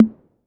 Tr8 Tom 05.wav